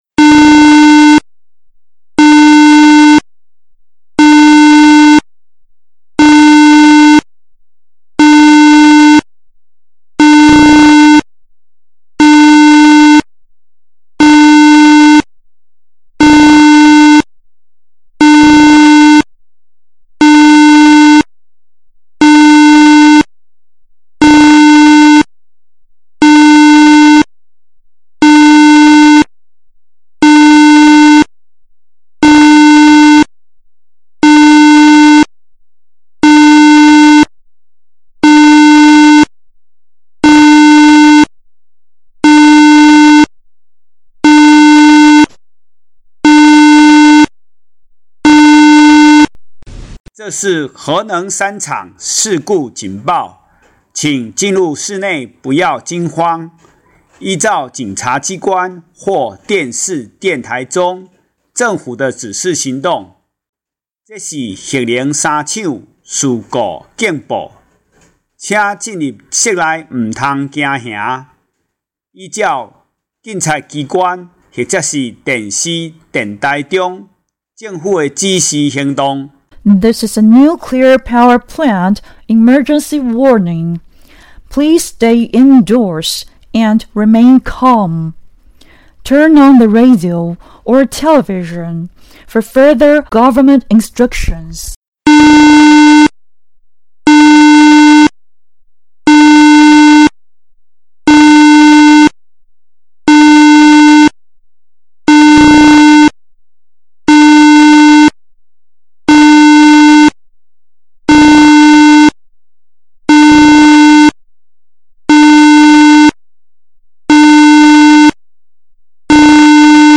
核子事故警報發布聲(♫)